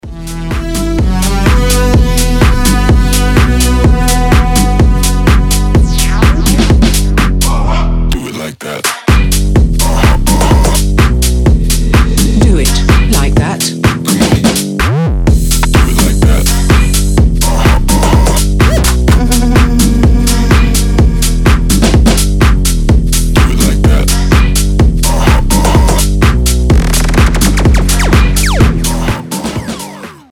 • Качество: 320, Stereo
EDM
мощные басы
Bass House
качающие
динамичные
Качёвый bass house